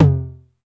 Index of /90_sSampleCDs/Club-50 - Foundations Roland/KIT_xTR909 Kits/KIT_xTR909 2
TOM XC.TOM04.wav